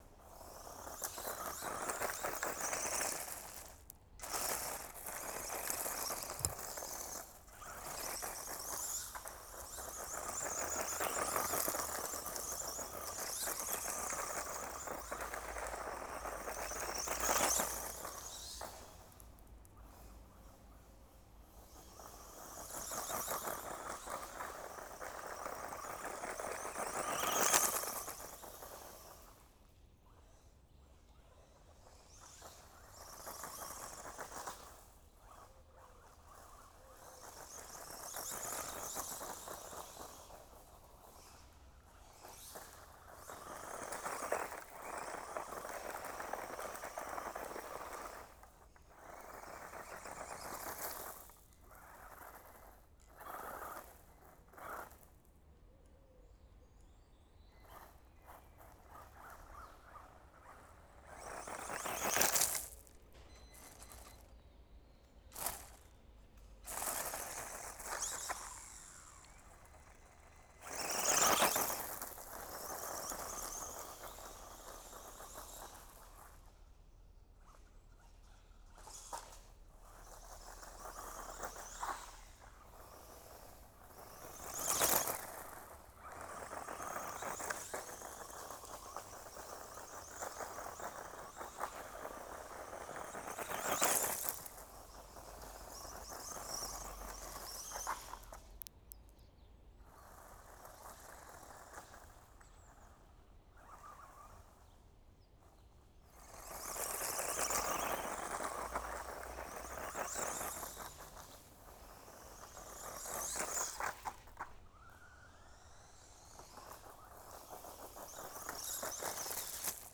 Scale electric car
Este coche radio-controlado puede llegar a una velocidad de hasta 50 Km/h. Al ser eléctrico, el sonido de las piedras volando se hace más evidente.
[ENG] This radio-controlled car can reach a speed of 50 km / h.
cotxe-teledirigit.wav